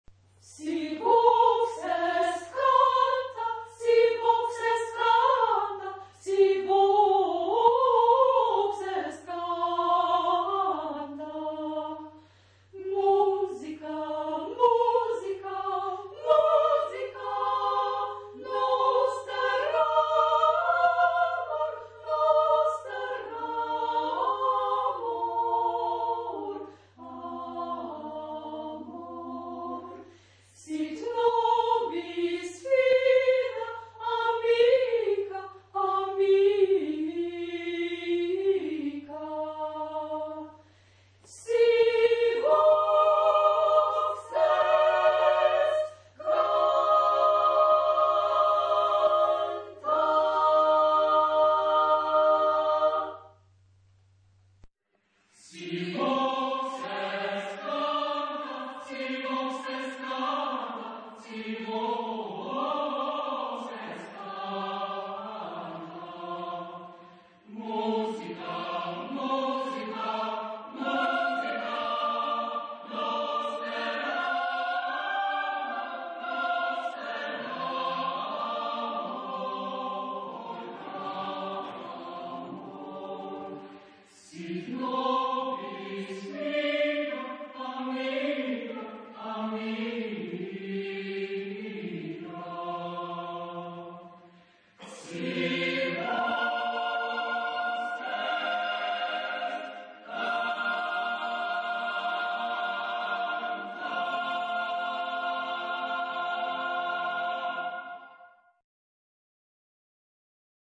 Tonalité : fa majeur